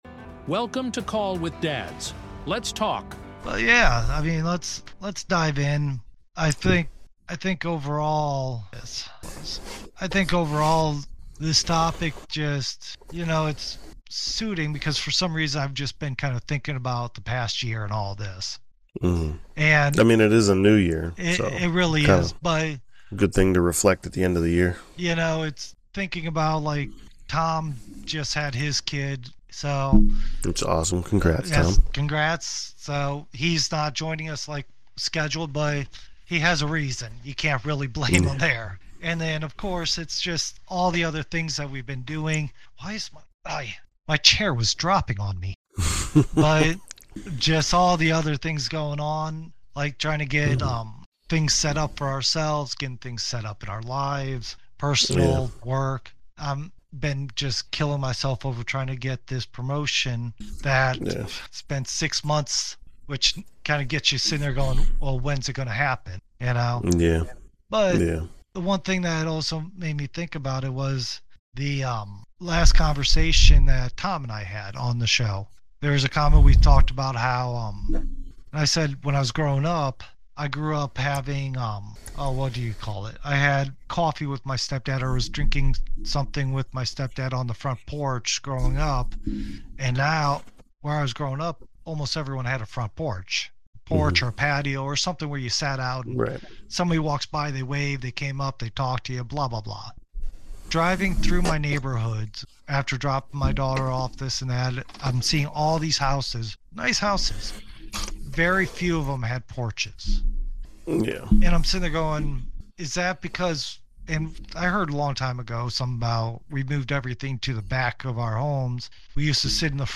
No guest this episode—it’s a special duo reflection with hosts